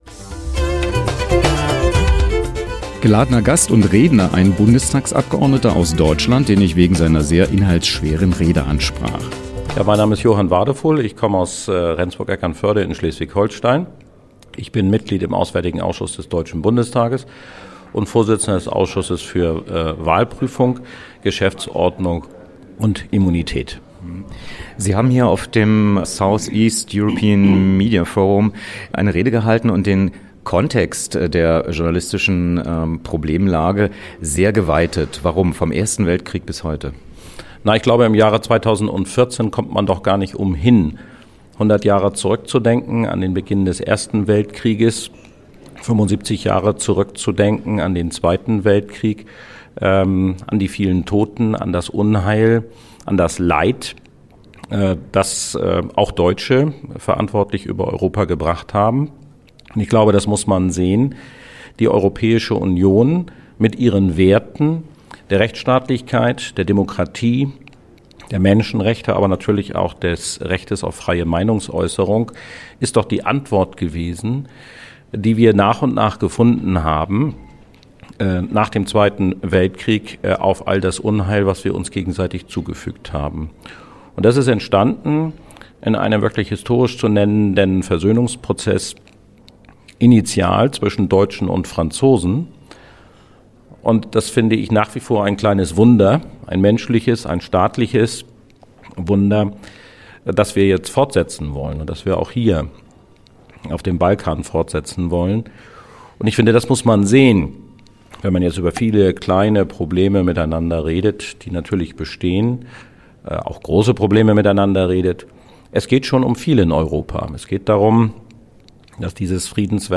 Wer: Dr. Johann David Wadephul, MdB, CDU
Was: Interview am Rande des VIII. SEEMF 2014
Wo: Skopje, Hotel HolidayInn